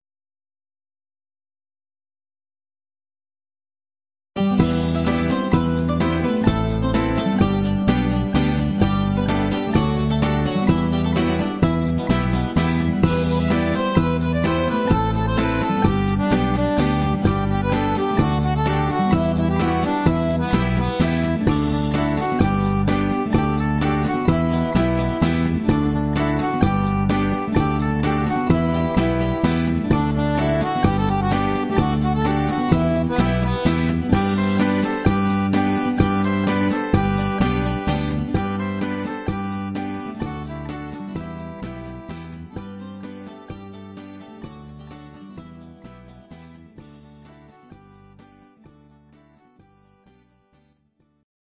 Greek Zeimpekiko Aptaliko